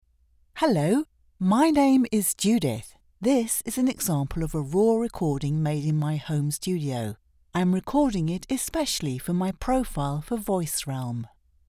Female
English (British)
Studio Quality Sample
My Home Studio Quality
1121Studio_Quality_Sample_VOICEREALM.mp3